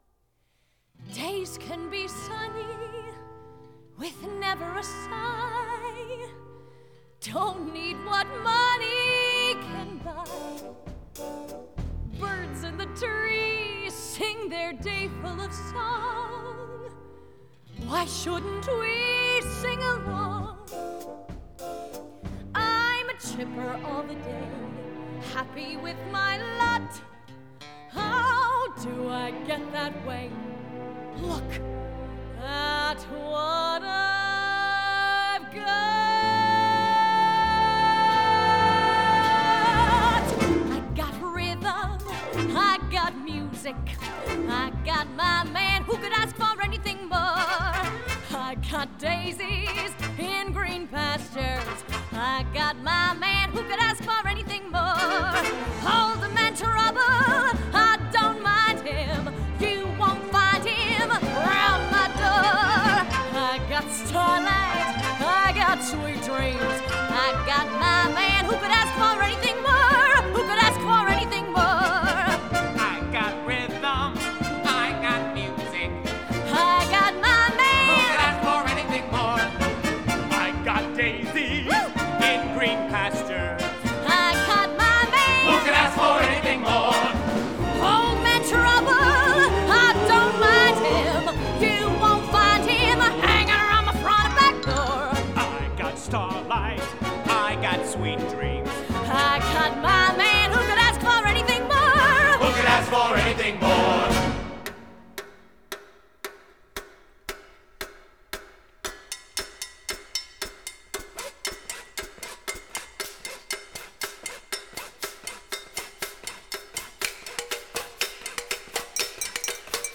1930   Genre: Musical   Artist